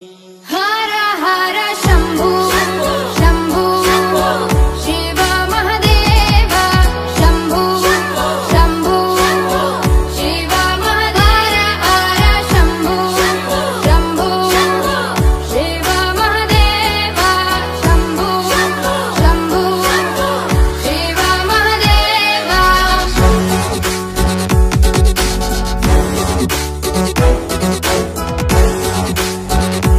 devotional music genre